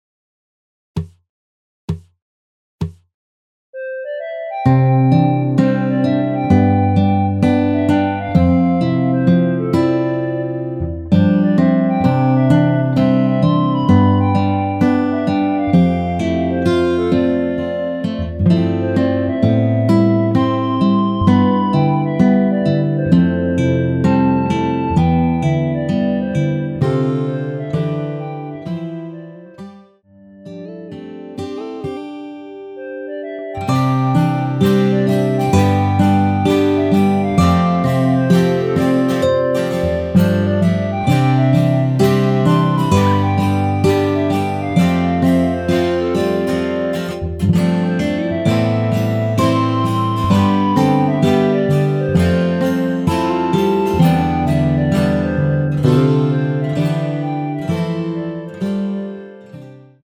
여자키 멜로디 포함된 MR 입니다.
전주없이 시작 하는 곡이라 카운트 넣어 놓았습니다.(미리듣기 참조)
원키에서(+9)올린 멜로디 포함된 MR입니다.
앞부분30초, 뒷부분30초씩 편집해서 올려 드리고 있습니다.